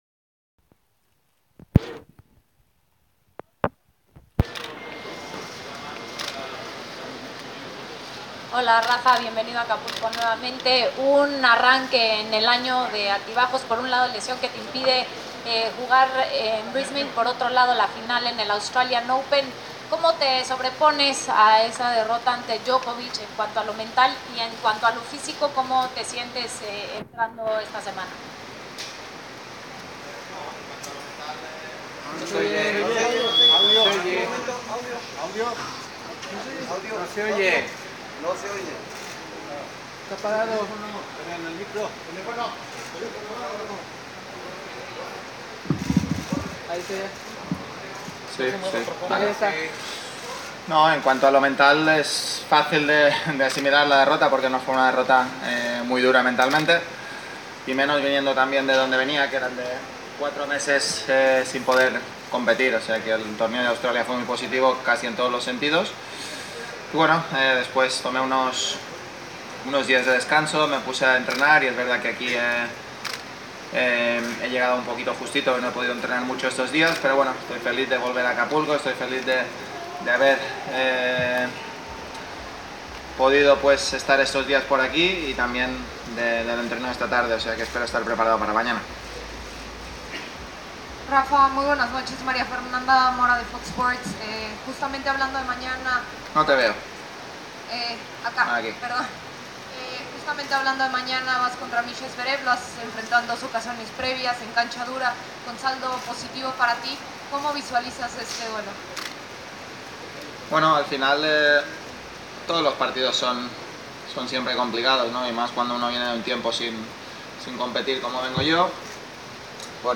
Press Conference – Rafael Nadal (25/02/2019)